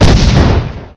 laserBang3.ogg